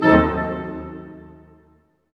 Index of /90_sSampleCDs/Roland L-CD702/VOL-1/HIT_Dynamic Orch/HIT_Tutti Hits